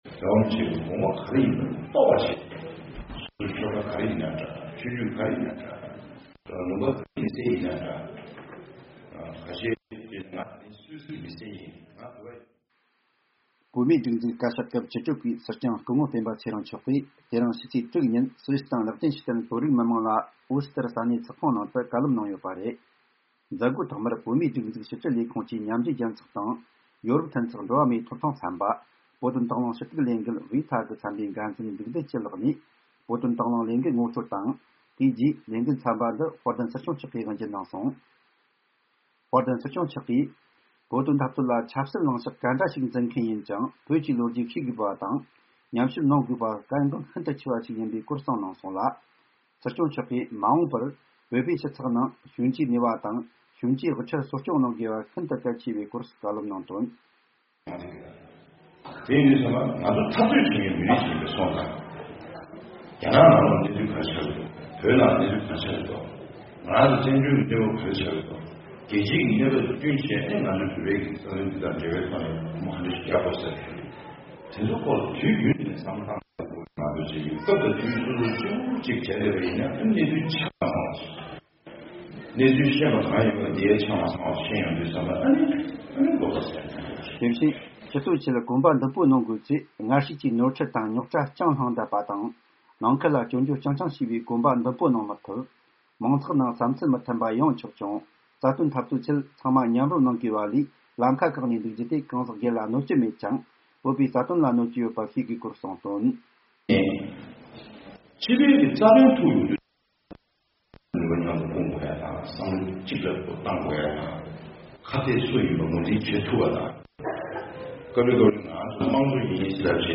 སྲིད་སྐྱོང་མཆོག་ནས་སུད་སི་དང་ལིག་ཏེན་སི་ཊན་བོད་རིགས་མི་མང་ལ་གསུང་བཤད།